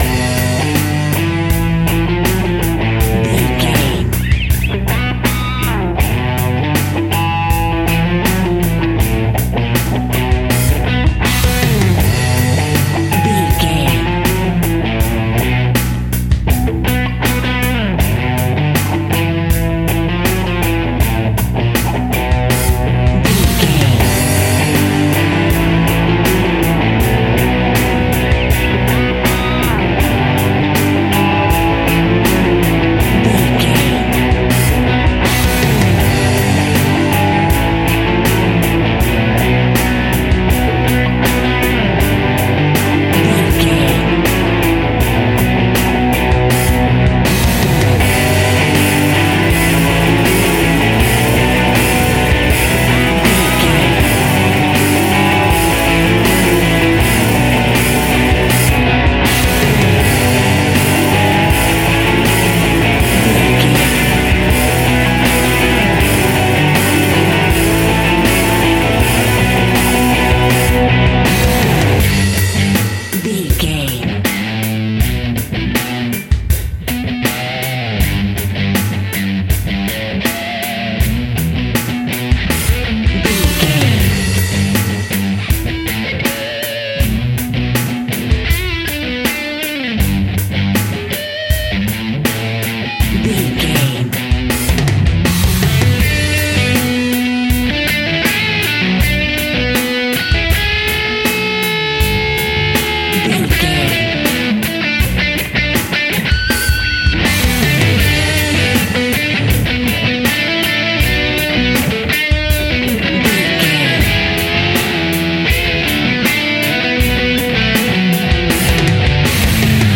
Epic / Action
Aeolian/Minor
heavy metal
blues rock
distortion
hard rock
Instrumental rock
drums
bass guitar
electric guitar
piano
hammond organ